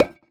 Minecraft Version Minecraft Version 1.21.5 Latest Release | Latest Snapshot 1.21.5 / assets / minecraft / sounds / block / decorated_pot / insert4.ogg Compare With Compare With Latest Release | Latest Snapshot